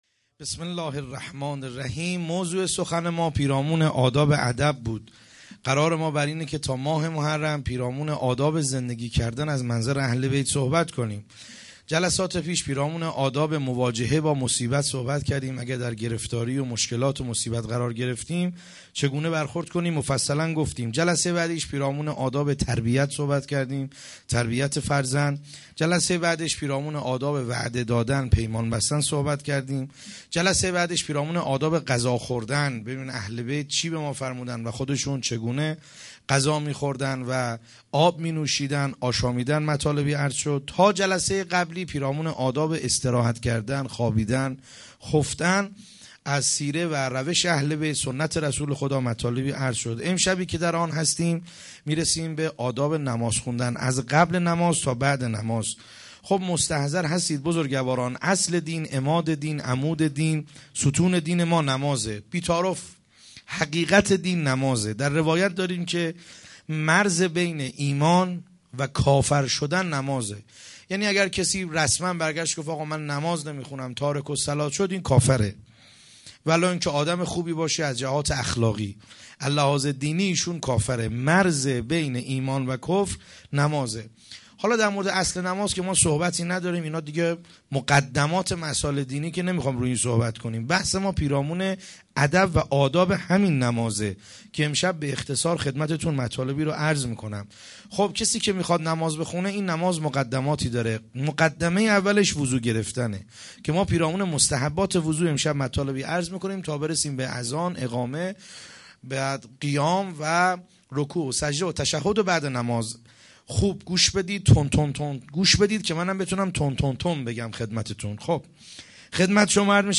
0 0 سخنرانی